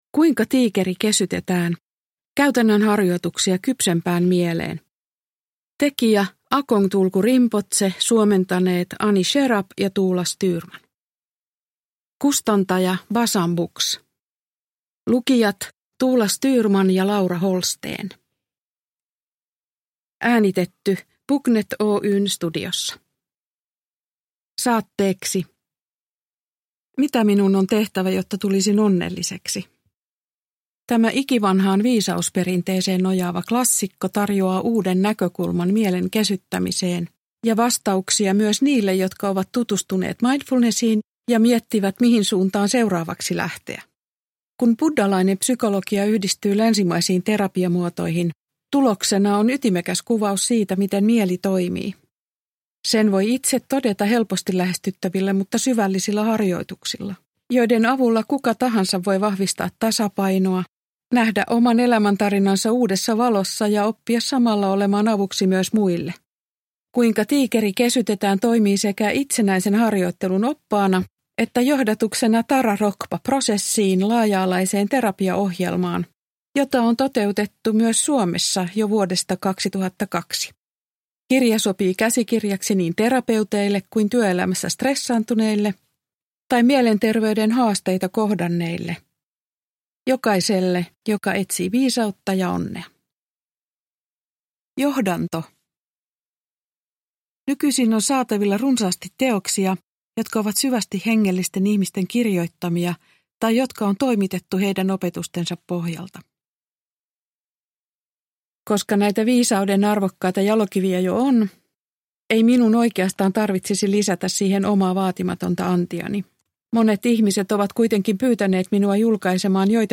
Kuinka tiikeri kesytetään (ljudbok) av Akong Tulku Rinpoche